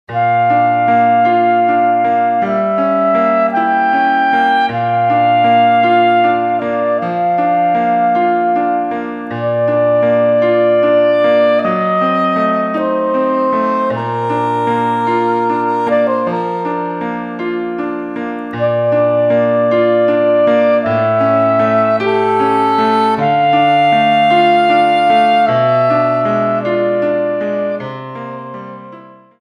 Saxophone en Sib et Piano